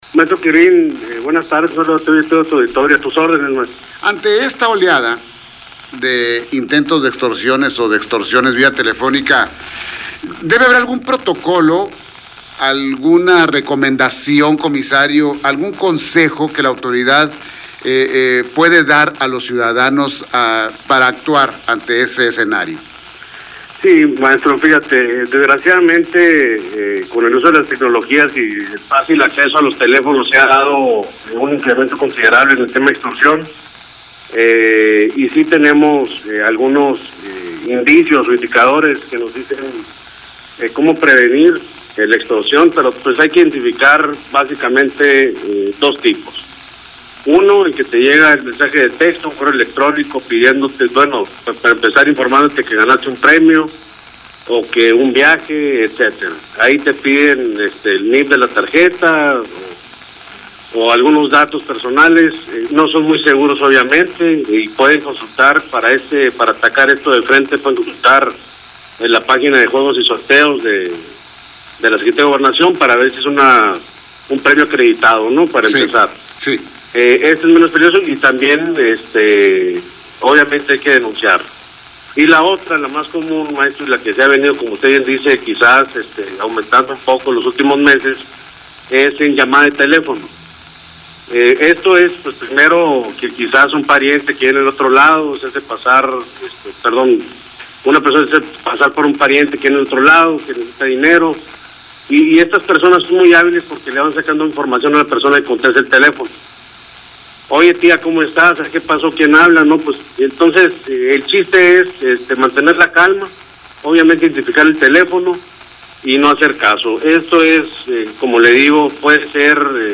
Ante la oleada de extorsiones vía telefónica hay protocolos a seguir, señaló el comisario de la Policía Preventiva y Tránsito Municipal, Jorge Andrés Suilo Orozco.
En entrevista para Las Noticias «De Primera Mano»